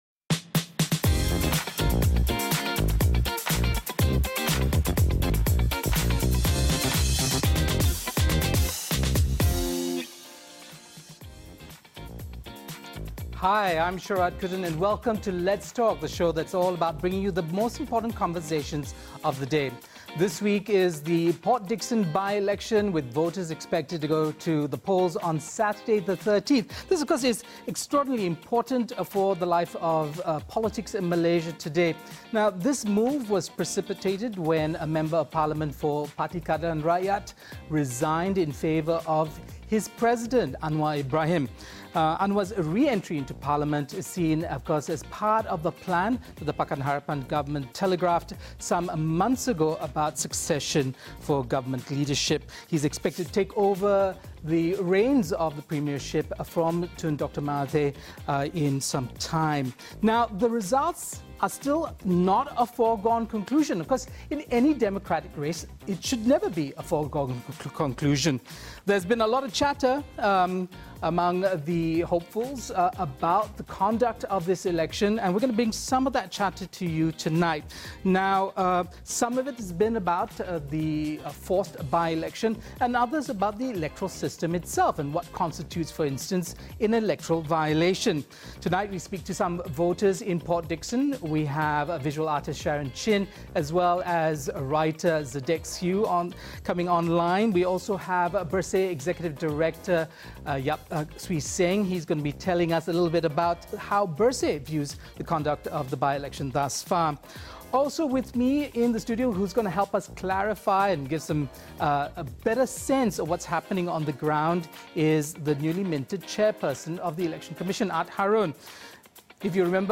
There's a lot chatter about this move to force a by-election as well as the electoral system as whole and what exactly constitutes an election violation. Tonight we speak to a few voters in PD